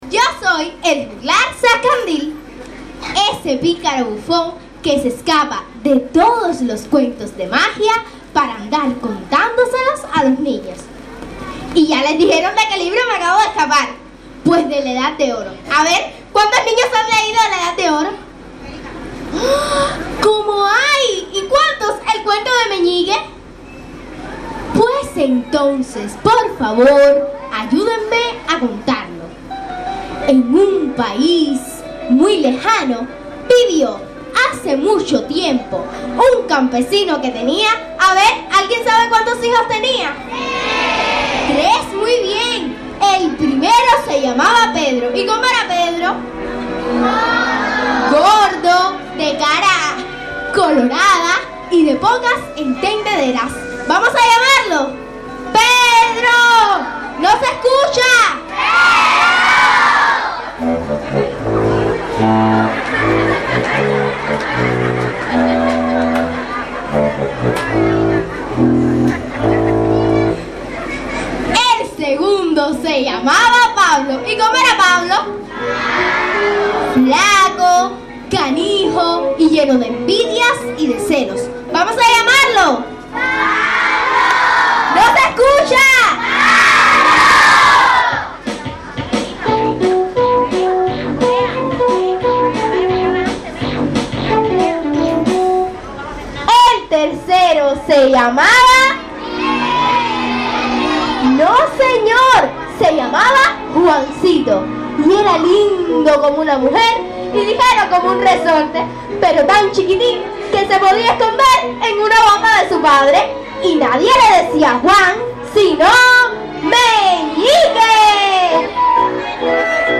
Hasta la comunidad de Oliva y el poblado de San Antonio de Cabezas llegaron las abejas para deleitar a los presentes con sus interpretaciones y buen arte.
Meñique fue la obra presentada por el grupo de teatral infantil. Entre canciones, poesías y juegos de participación lograron unir en matrimonio a la princesa con el audaz e inteligente Juancito.